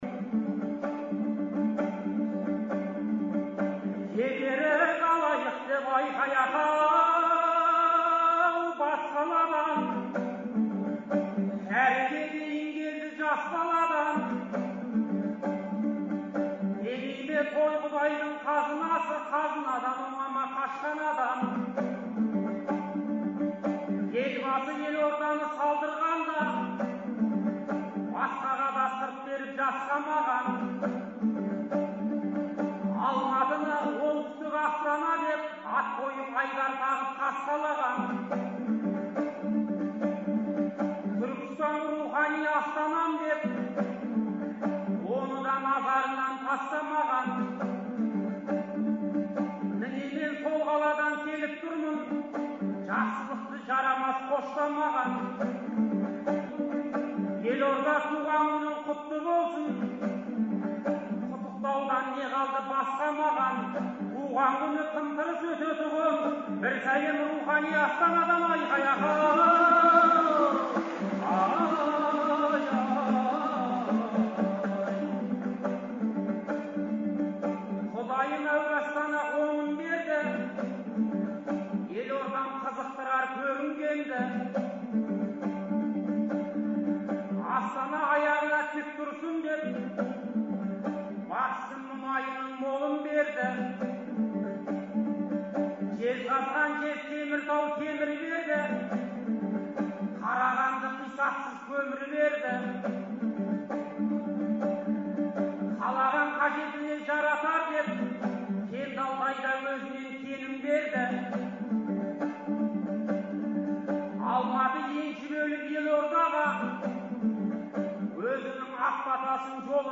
Шілденің 8-9-ы күндері Астанадағы «Қазақстан» орталық концерт залында «Ел, Елбасы, Астана» деген атпен ақындар айтысы өтті. Алғашқы күні айтысқан 20 ақынның арасынан іріктеліп шыққан он адам екінші күні бес жұп болды.